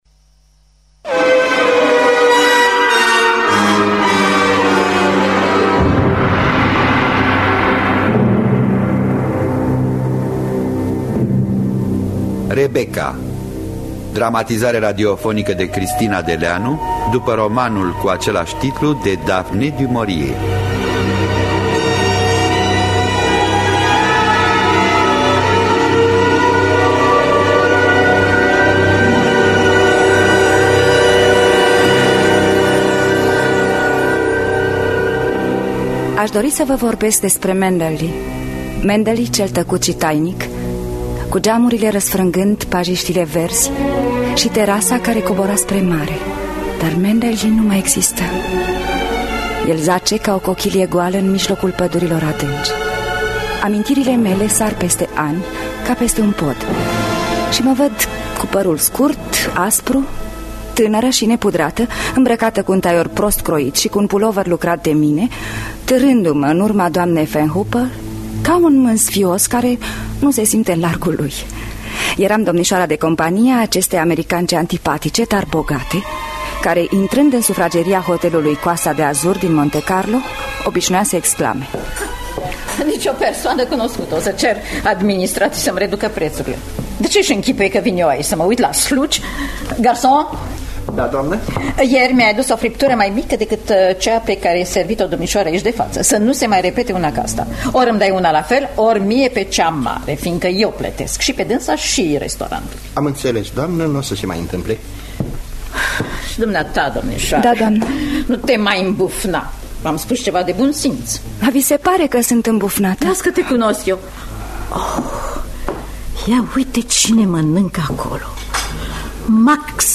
Dramatizarea radiofonică de Cristina Deleanu.